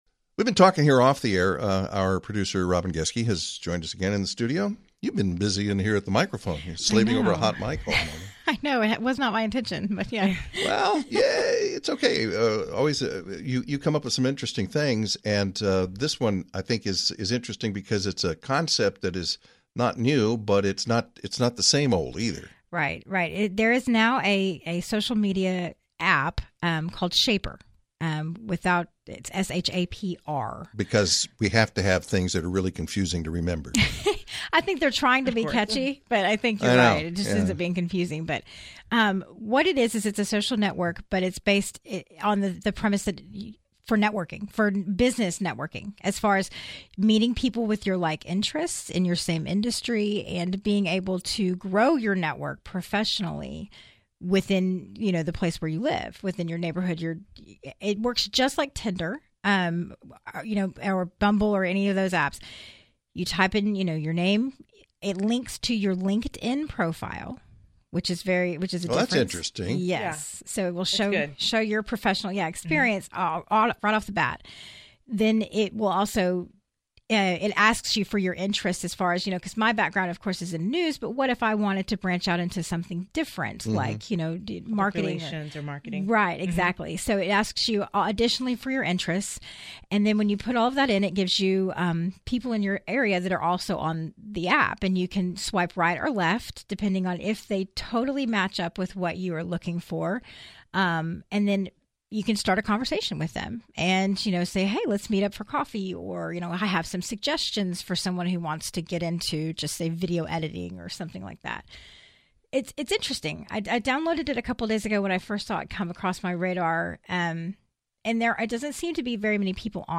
DALLAS (KLIF/WBAP) — A new social networking app is geared toward helping you expand your professional network.